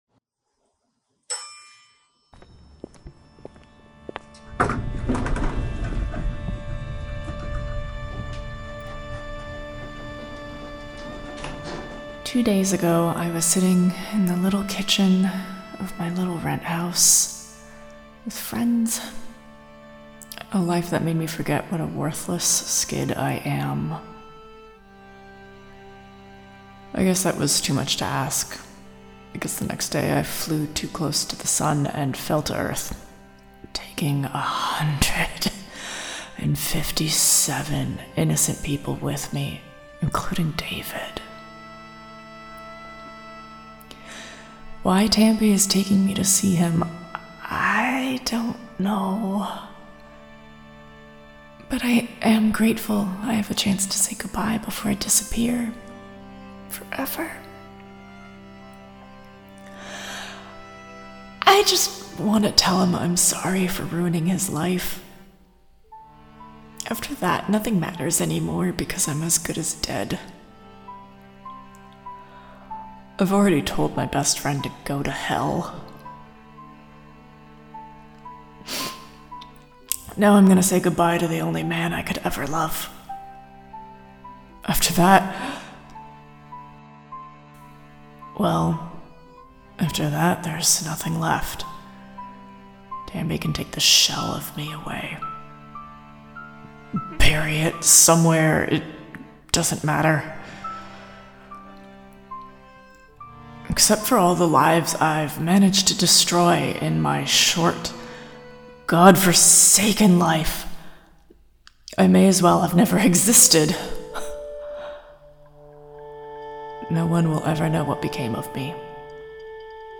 Strangers In Paradise – The Audio Drama – Book 8 – My Other Life – Episode 7 – Two True Freaks
The Ocadecagonagon Theater Group